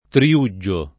Triuggio [ tri- 2JJ o ]